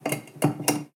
Apertura de un envase metálico 2
Cocina - Zona de preelaboración
Sonidos: Acciones humanas
Sonidos: Hogar